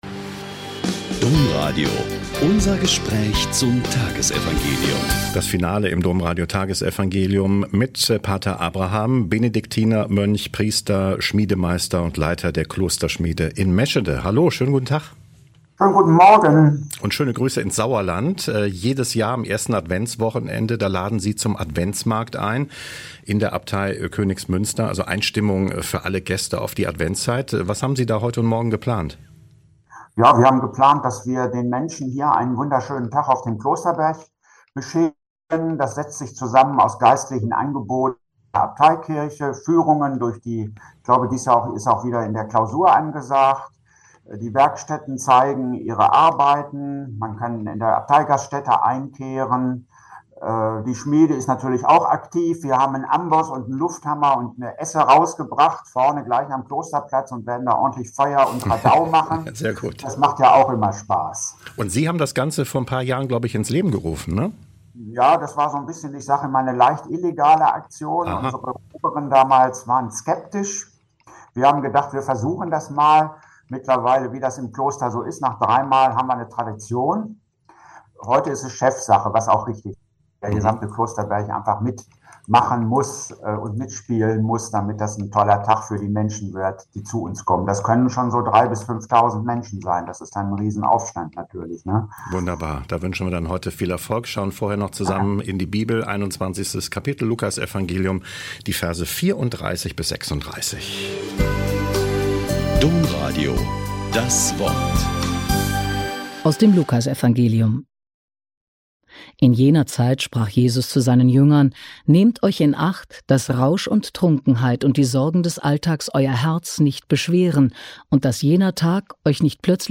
Lk 21,34-36 - Gespräch